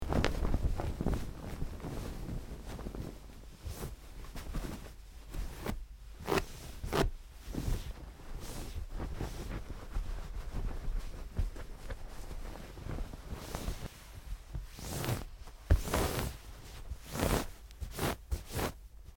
MakingUpBedSheets PE382201
Making Up The Bed With Sheets And Blanket, X2